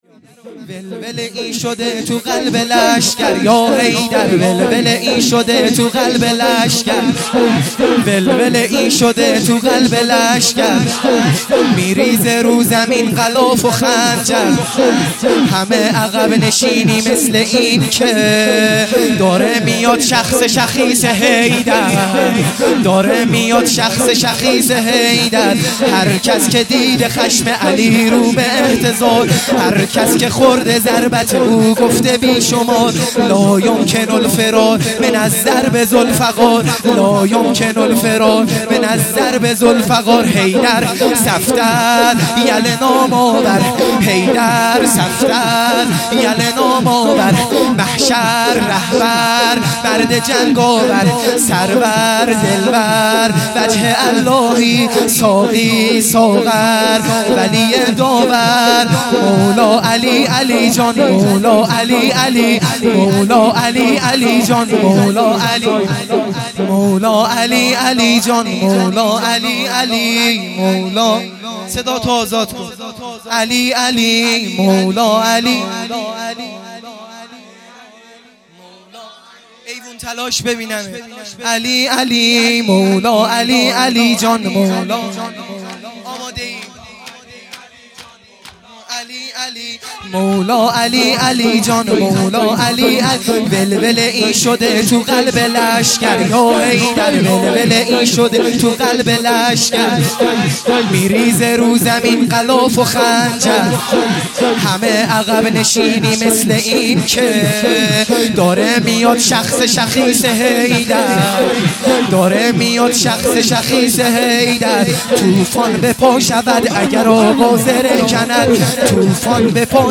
شور | ولوله ای شده تو قلب لشگر